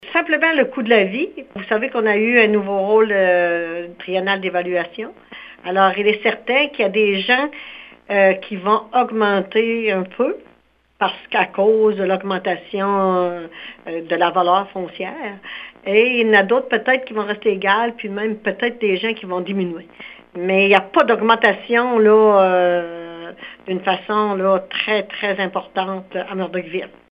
Tout comme l’an dernier, les citoyens n’écoperont pas de hausses importantes de leurs comptes de taxes, selon la mairesse, Délisca Ritchie Roussy :